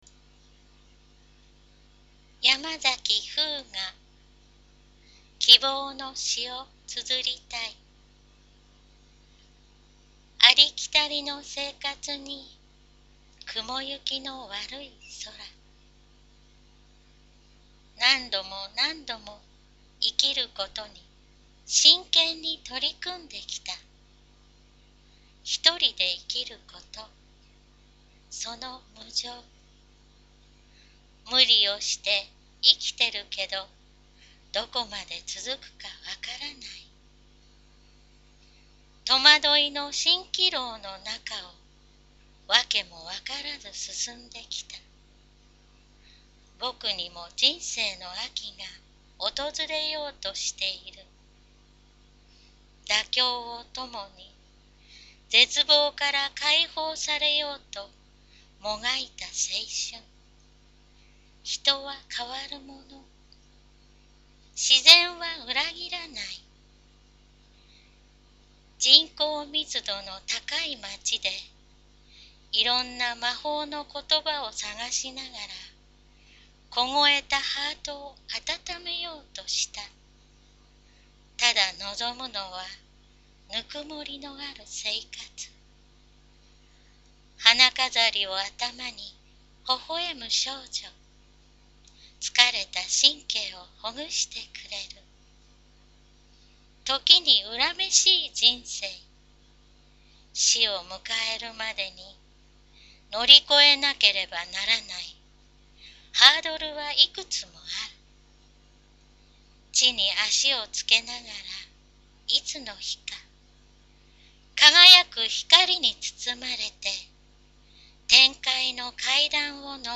poemreadkibou001.mp3